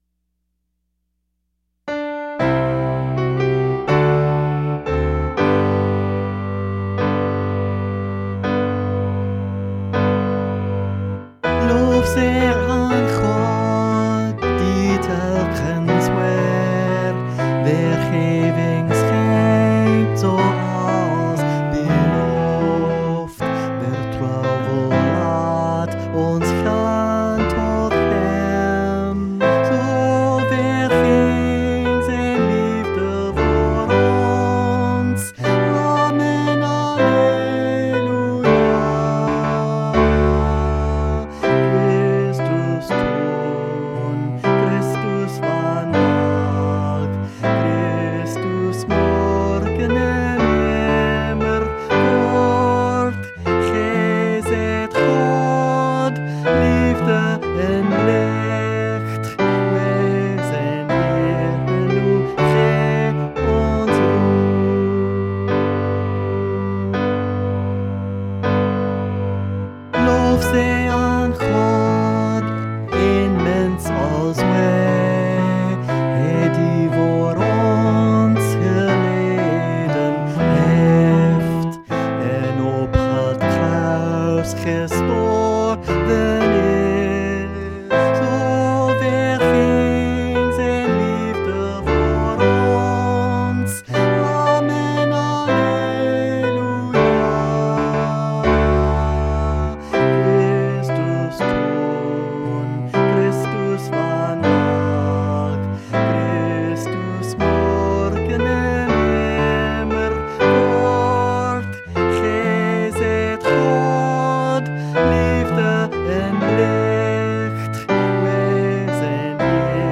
sung ALL   Voice | Downloadable